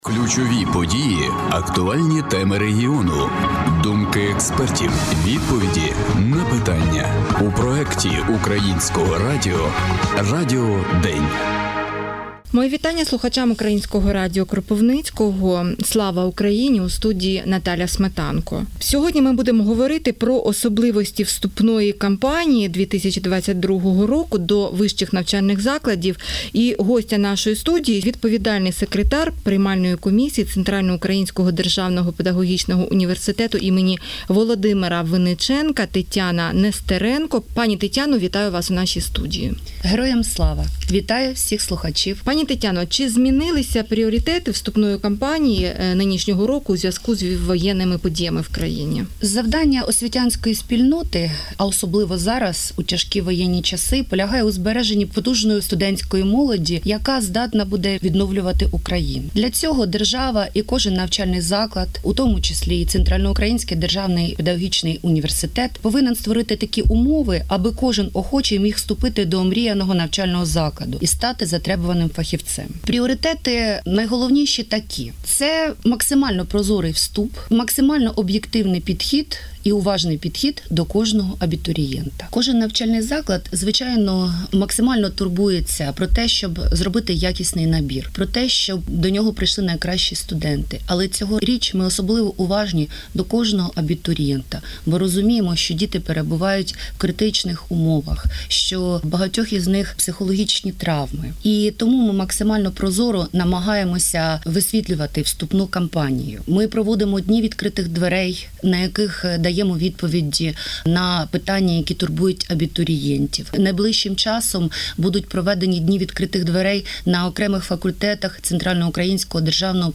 Особливості вступної кампанії 2022 року - інтерв'ю